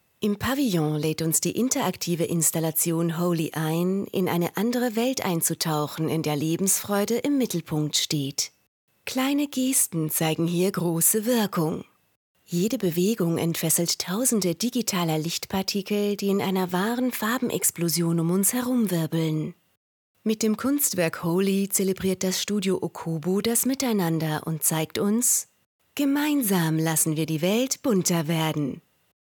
Audiodeskription